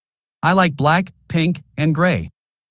Audio 1. I like black pink and grey_native